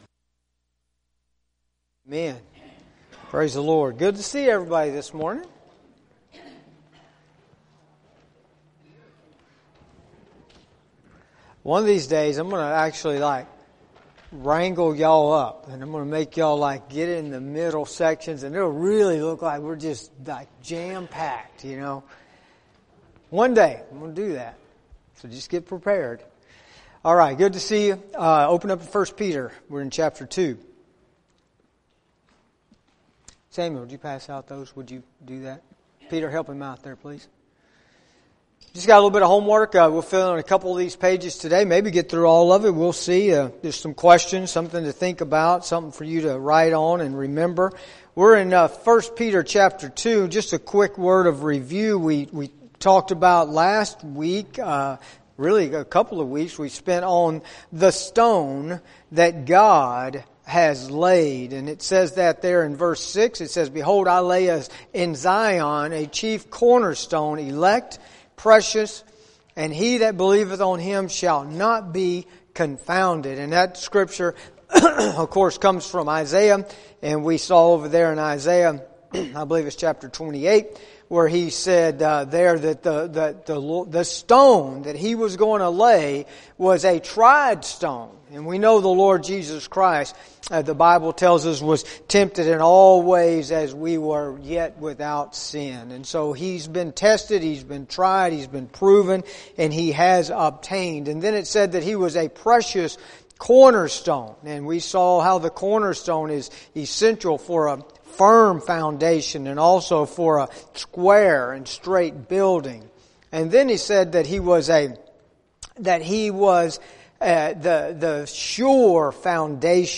Sunday School